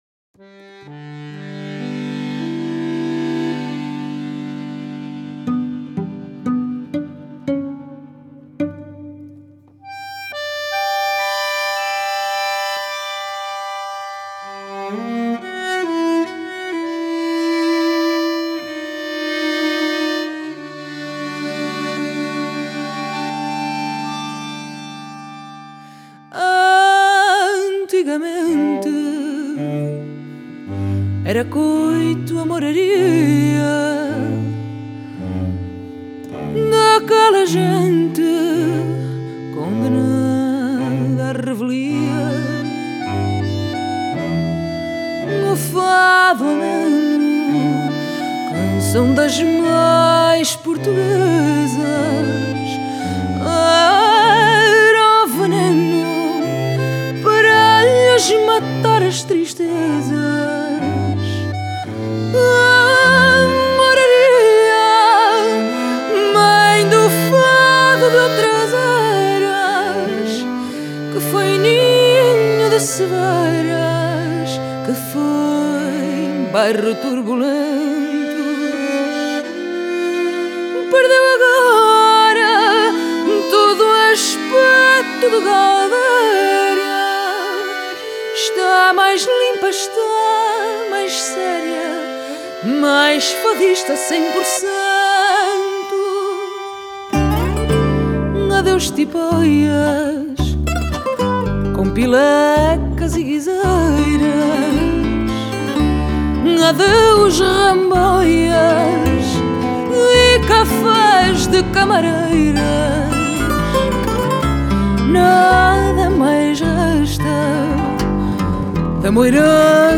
Genre: Fado